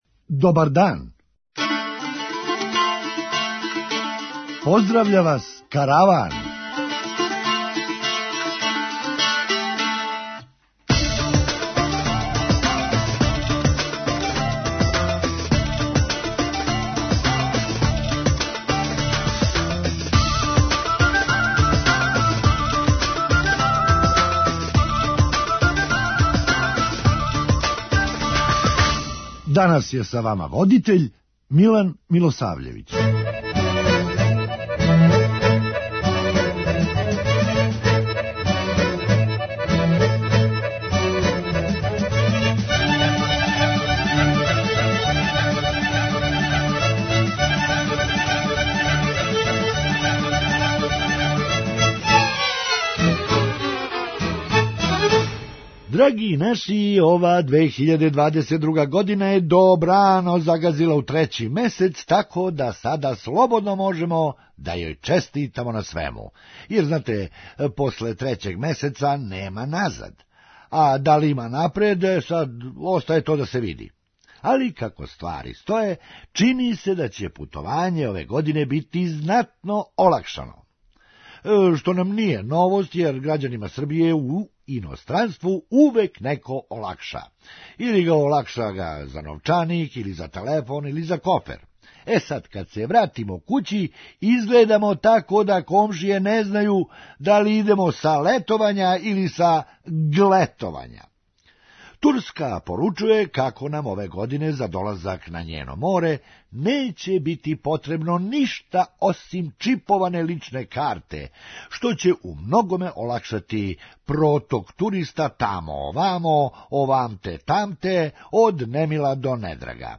Хумористичка емисија
Изгледа да је Ђоковић опет упутио неки сервис с неба па у ребра. преузми : 9.13 MB Караван Autor: Забавна редакција Радио Бeограда 1 Караван се креће ка својој дестинацији већ више од 50 година, увек добро натоварен актуелним хумором и изворним народним песмама.